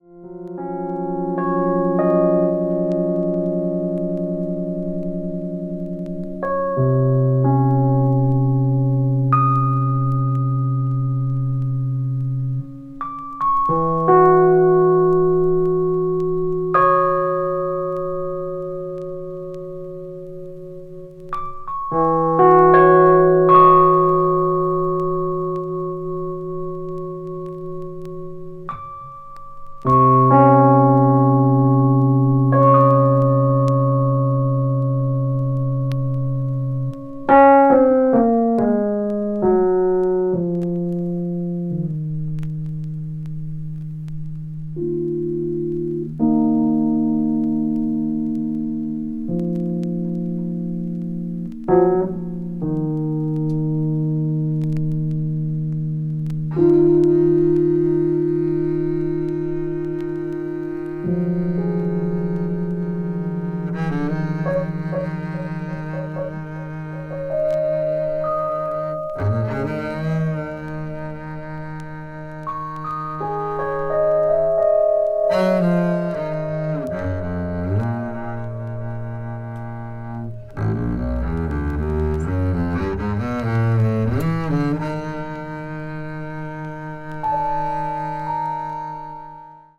avant-jazz   contemporary jazz   deep jazz   free jazz